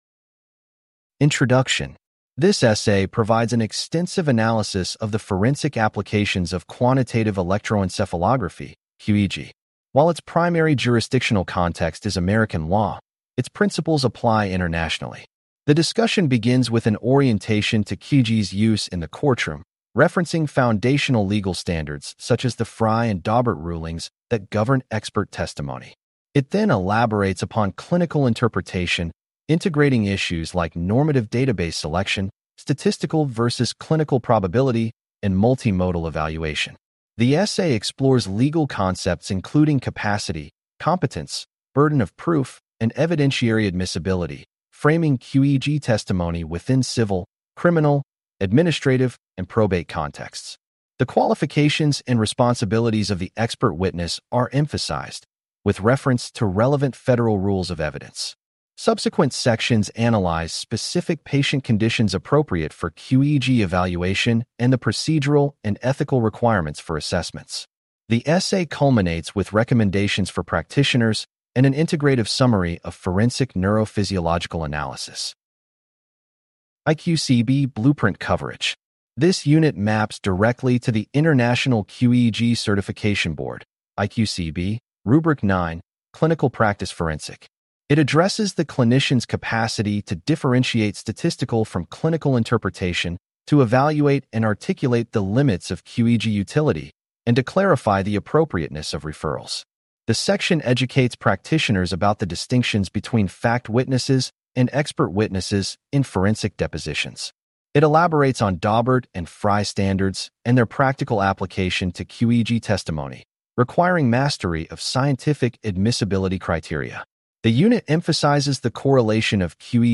Please click on the podcast icon below to hear a lecture over this unit.